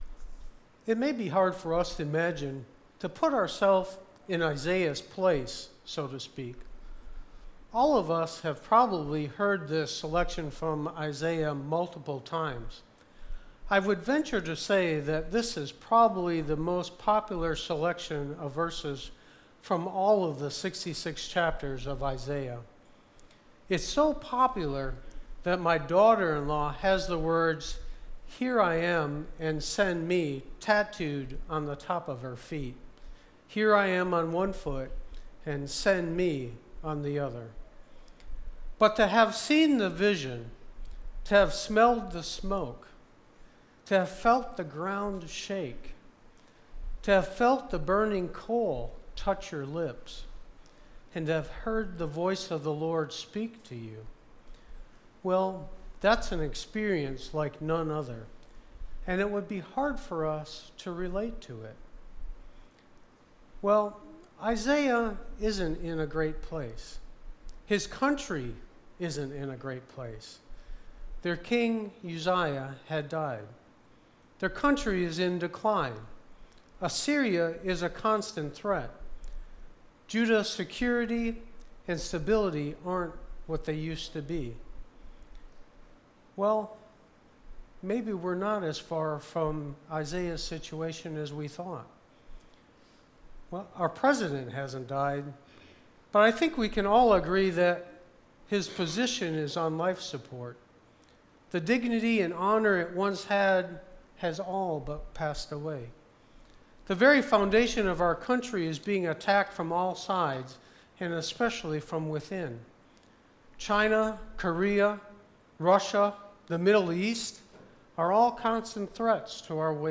Sermon-02-06.mp3